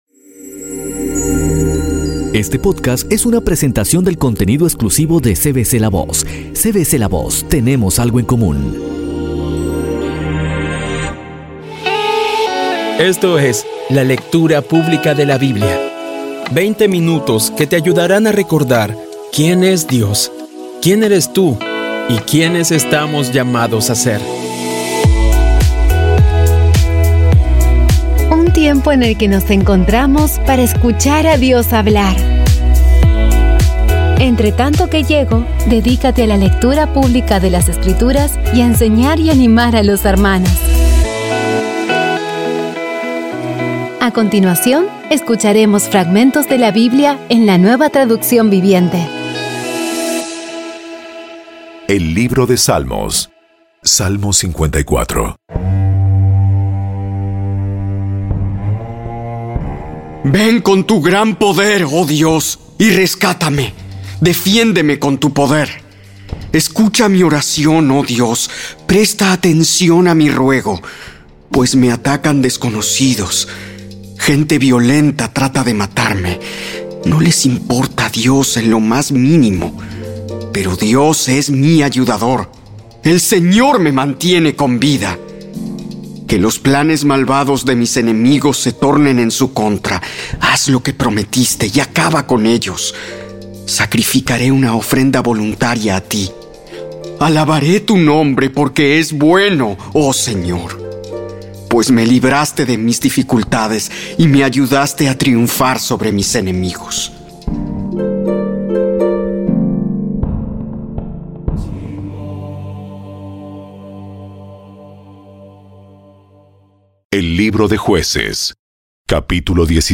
Audio Biblia Dramatizada Episodio 120
Poco a poco y con las maravillosas voces actuadas de los protagonistas vas degustando las palabras de esa guía que Dios nos dio.